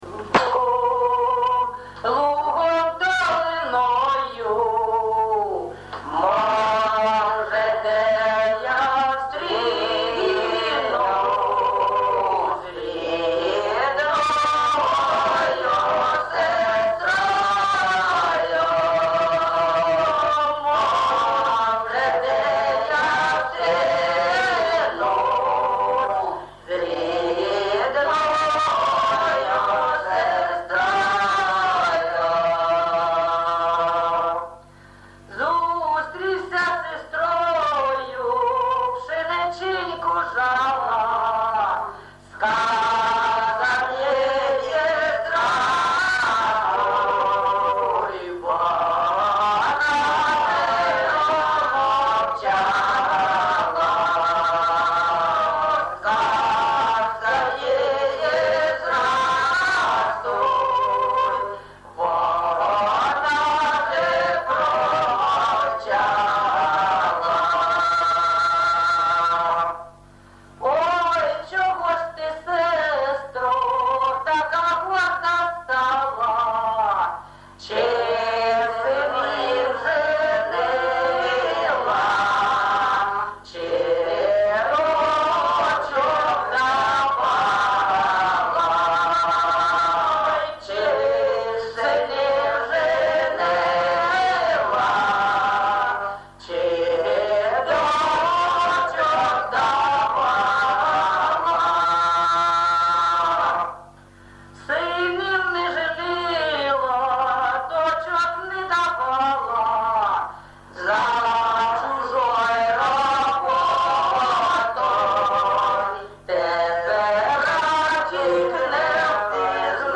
ЖанрПісні з особистого та родинного життя, Строкові
Місце записум. Єнакієве, Горлівський район, Донецька обл., Україна, Слобожанщина